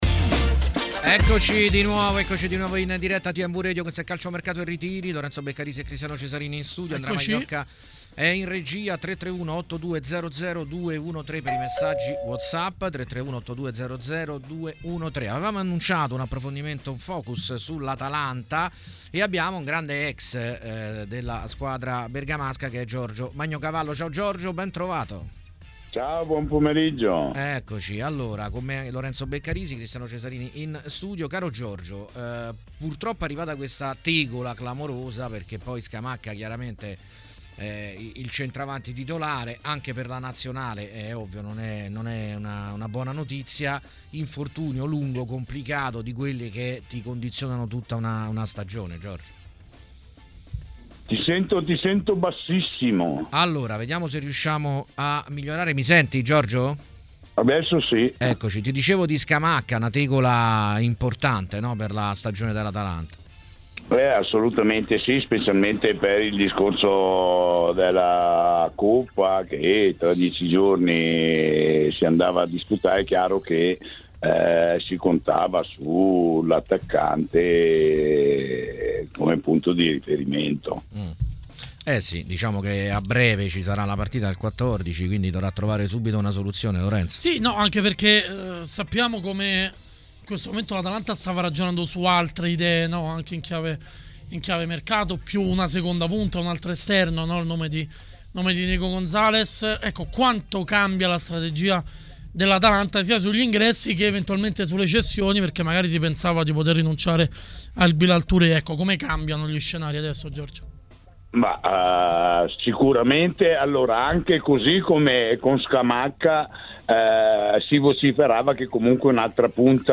Ospite di TMW Radio durante Calciomercato e Ritiri è stato l'ex calciatore